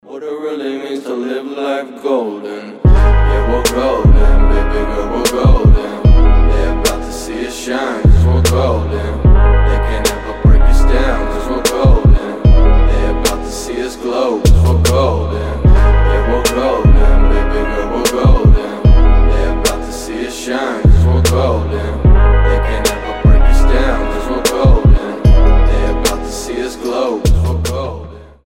• Качество: 320, Stereo
медленные
качающие
Chill